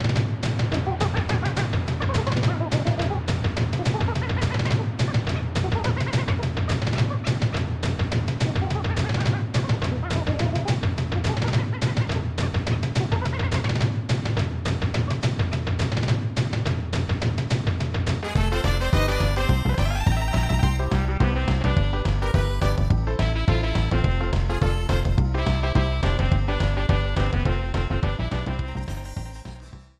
Title screen music